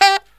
HIHITSAX01-R.wav